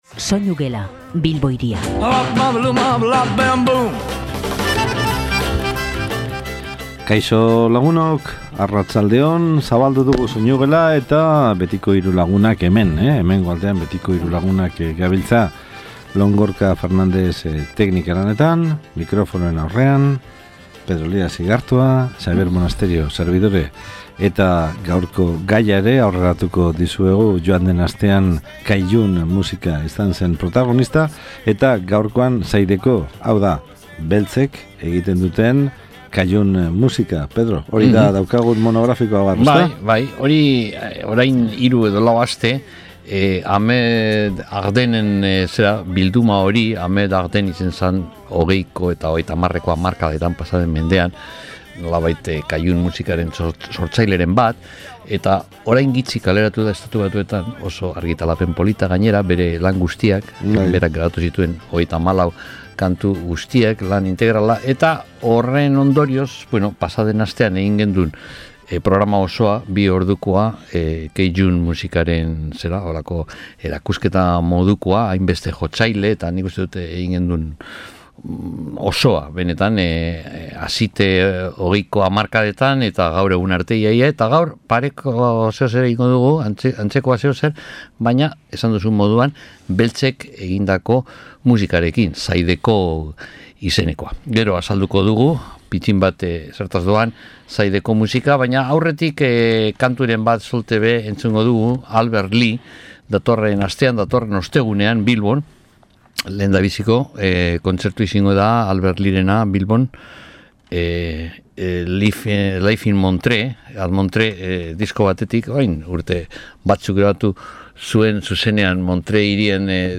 SOINUGELA: Zydeco musikaren doinuak entzungai | Bilbo Hiria irratia